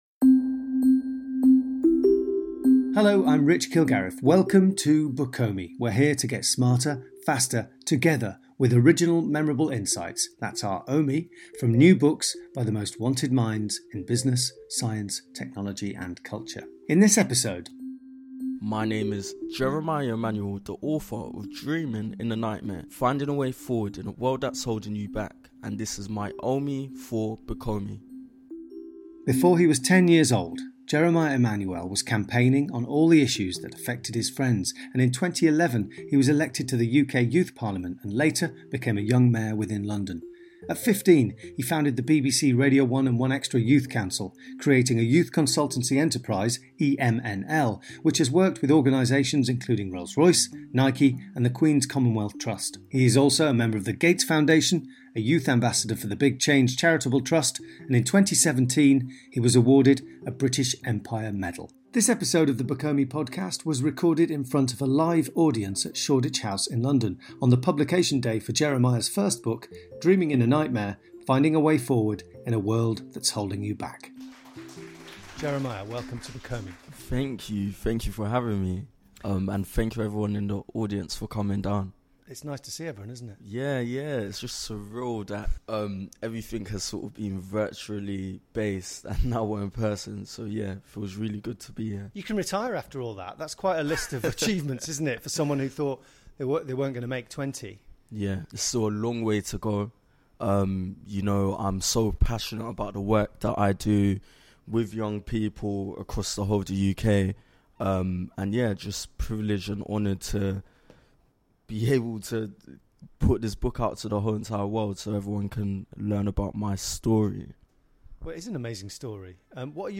This episode of the Bookomi podcast was recorded in front of a live audience at Shoreditch House in London